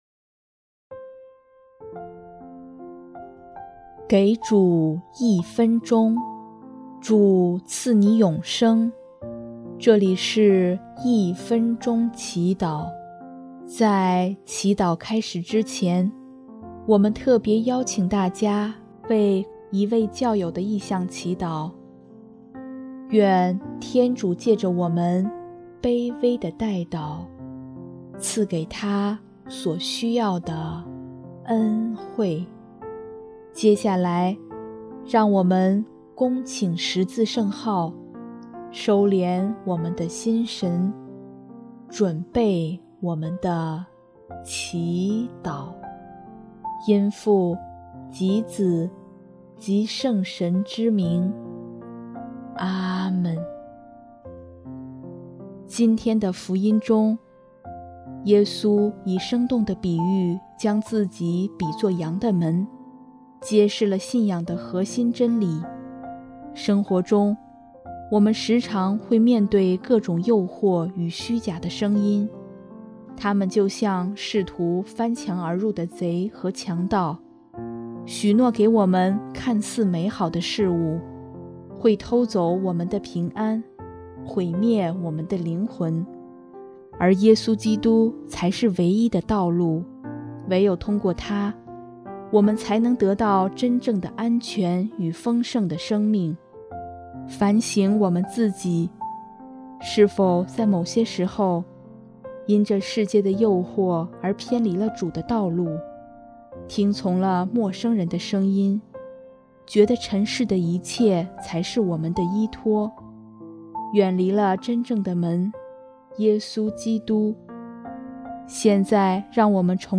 【一分钟祈祷】|5月12日 回归生命之门，在基督里寻得永恒依托
音乐：第四届华语圣歌大赛参赛歌曲《God with Me》（core：求主在新的一年指引、帮助、祝福自己，帮助自己达到今年计划的目标）